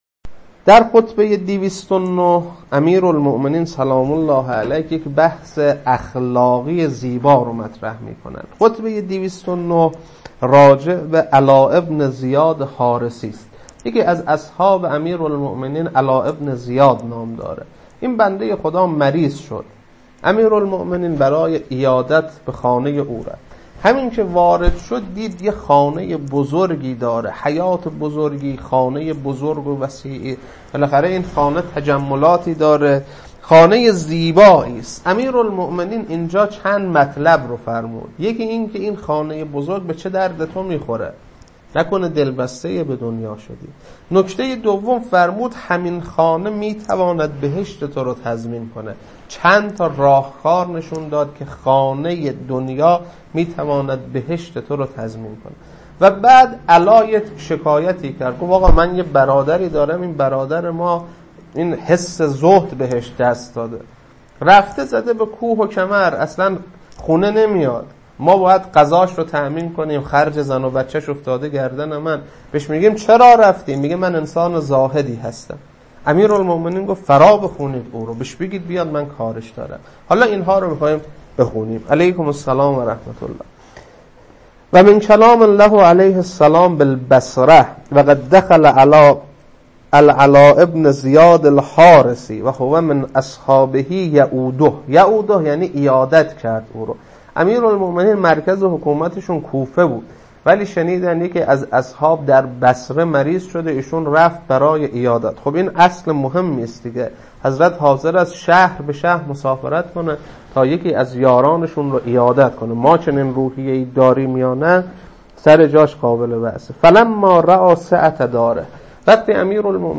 خطبه 209.mp3